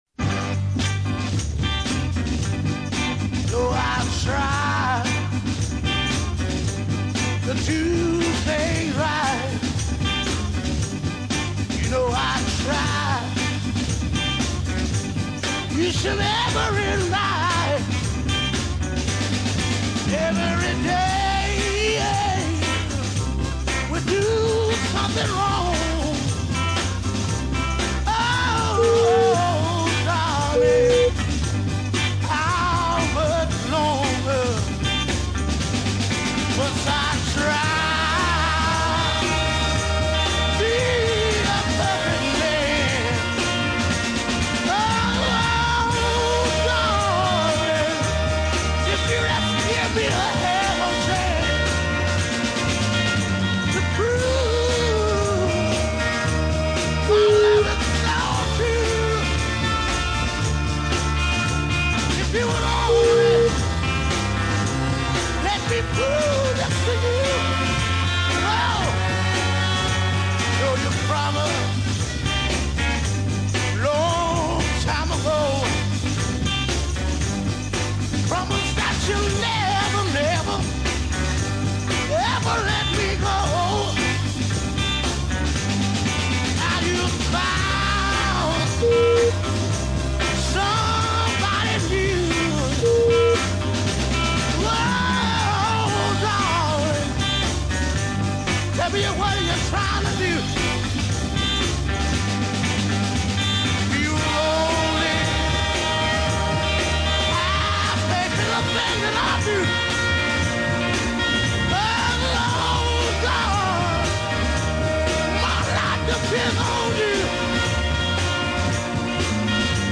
This is southern soul vocalising at its most heart rending.
deep soul music